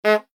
audio: Converted sound effects
KART_raceStart1.ogg